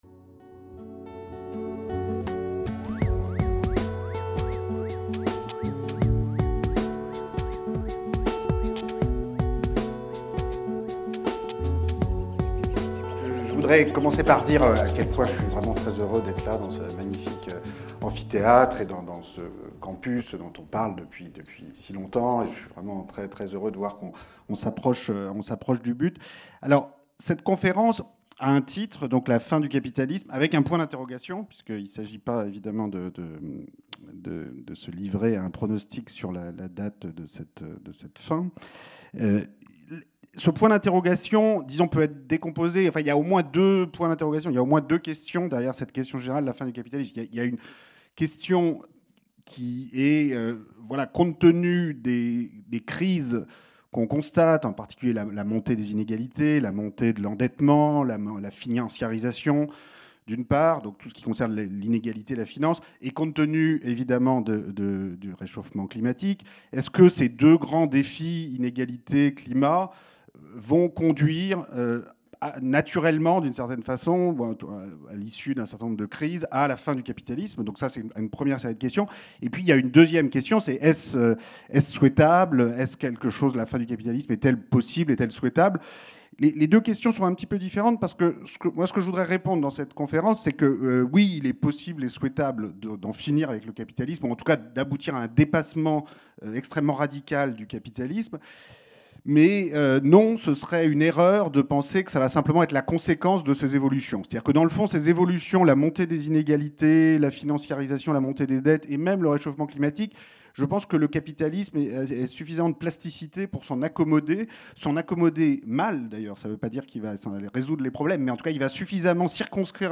Dans cette conférence, Thomas Piketty s’interroge sur la signification d’une possible « fin du capitalisme », ou plus précisément sur le type de transformation des rapports de propriété – ou de retour à des formes de rapports antérieurs – que sous-tendent les évolutions en cours.